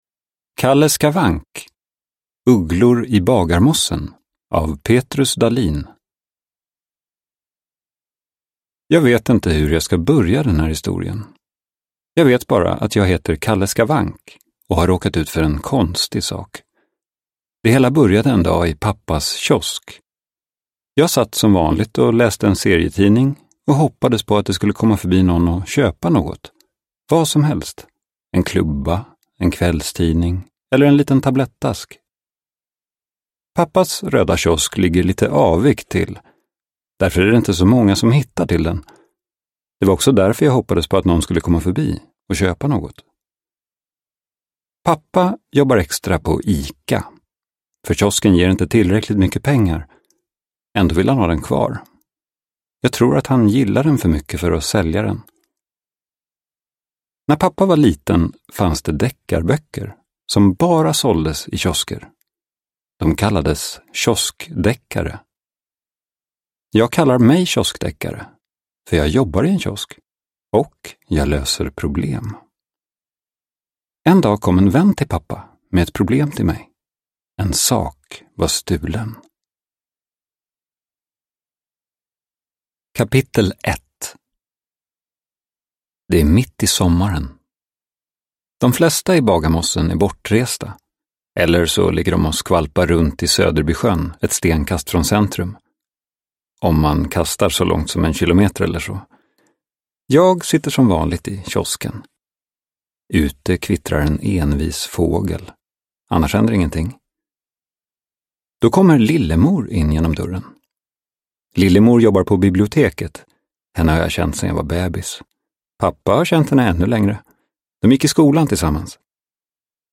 Ugglor i Bagarmossen – Ljudbok – Laddas ner